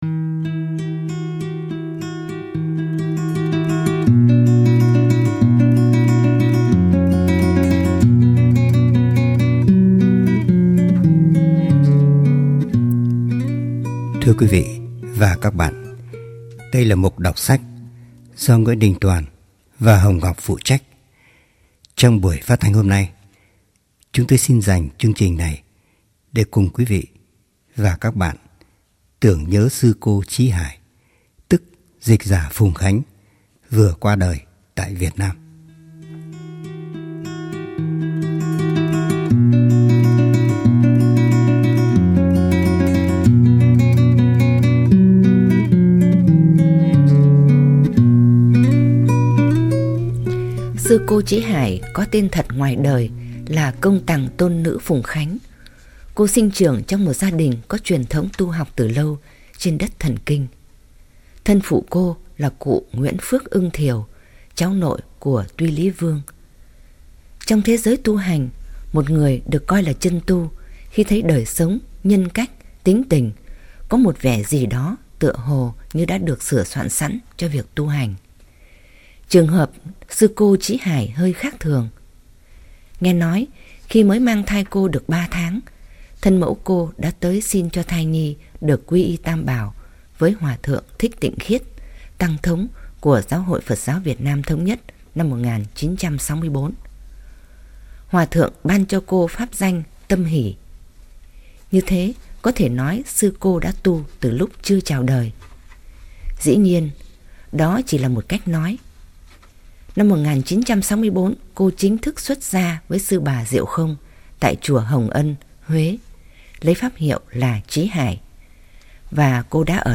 Biên sọan: Nguyễn Đình Tòan